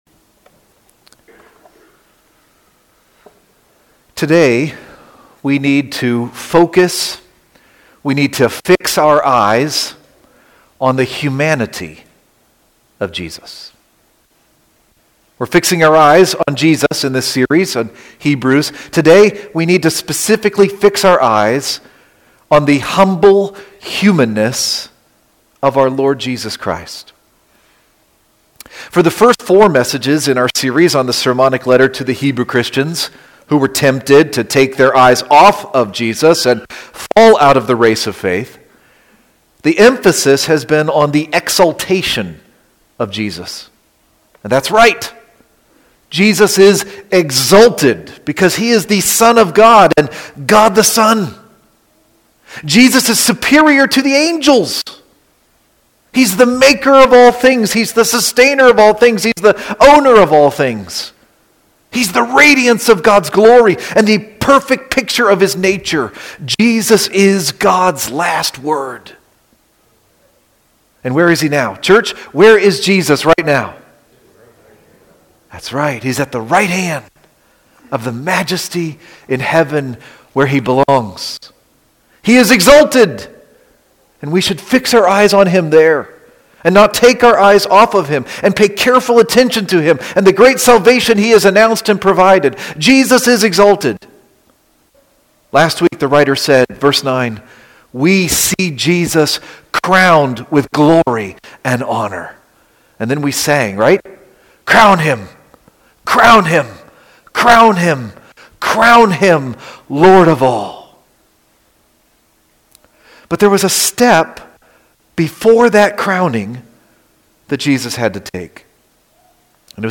Flesh and Blood :: March 1, 2026 - Lanse Free Church :: Lanse, PA